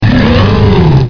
P3D-Legacy / P3D / Content / Sounds / Cries / 500.wav